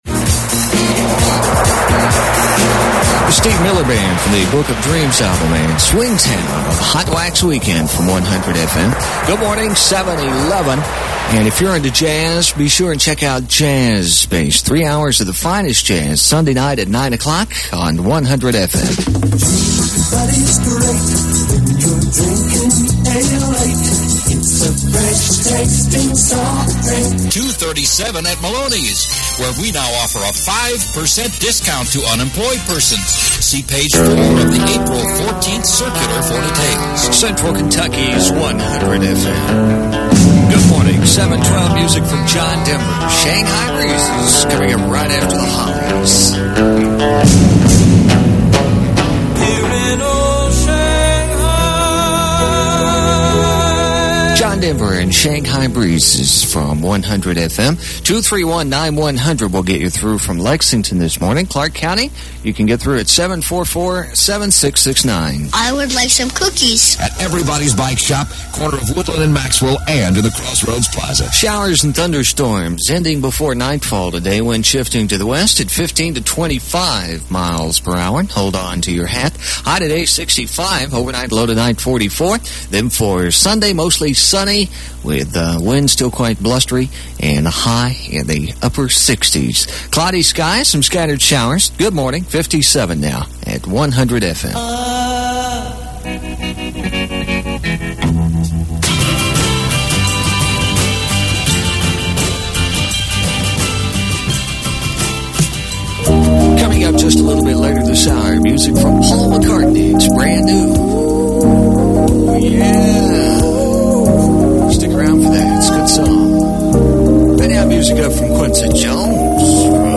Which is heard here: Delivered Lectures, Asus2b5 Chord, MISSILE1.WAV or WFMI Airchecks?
WFMI Airchecks